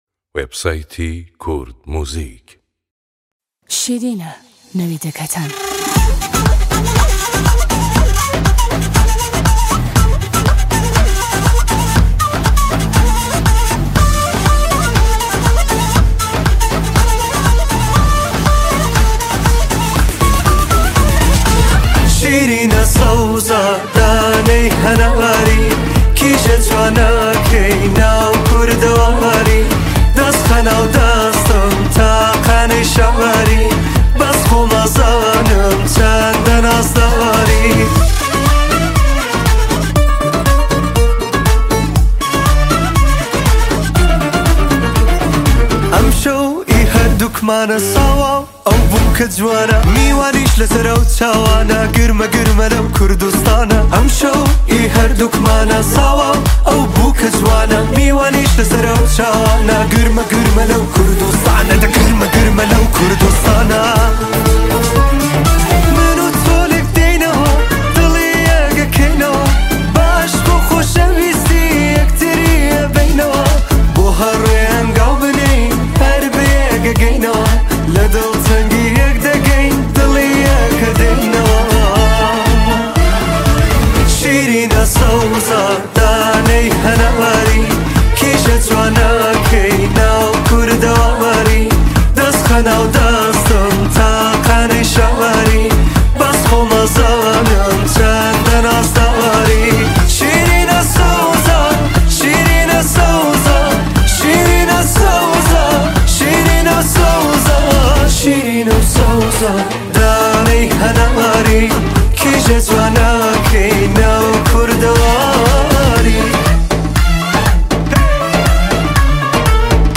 آهنگ کردی جدید